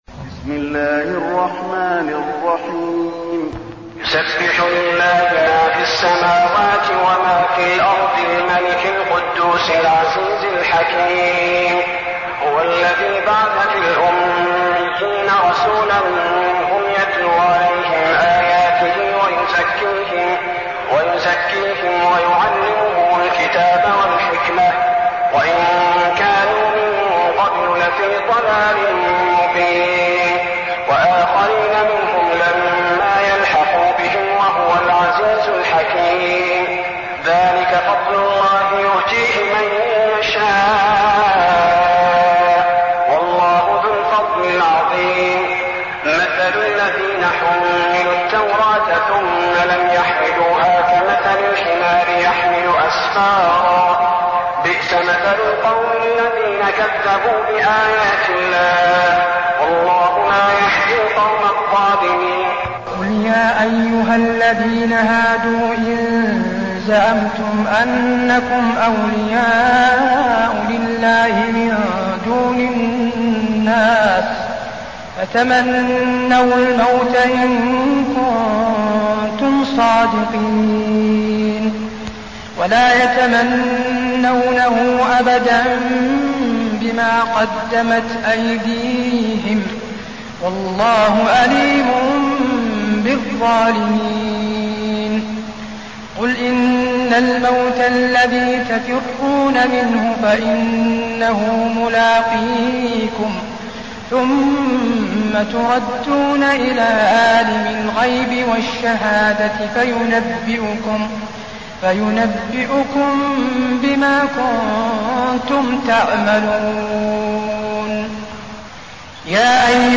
المكان: المسجد النبوي الجمعة